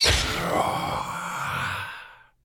beyond/Assets/Sounds/Enemys/SH_die2.ogg at main
SH_die2.ogg